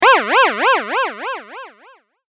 めまい.mp3